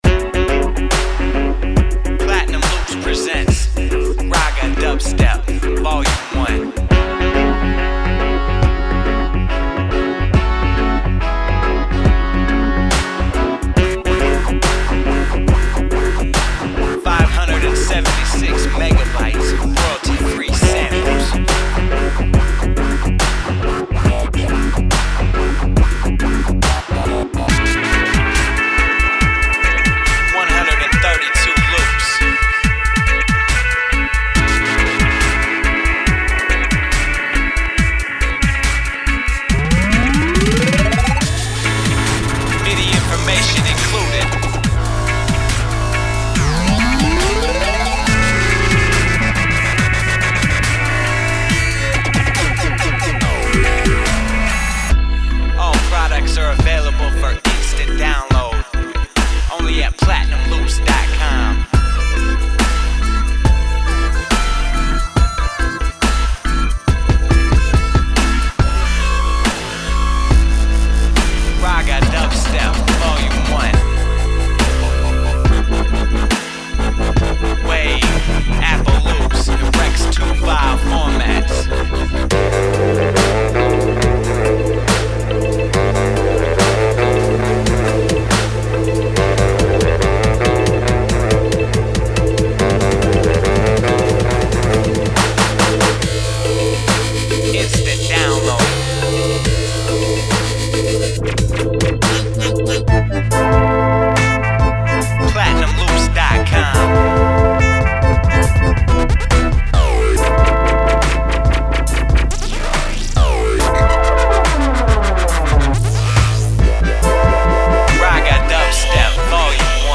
A slick collection of street ready dubstep loops.
Tempos are all 70 / 140 bpm.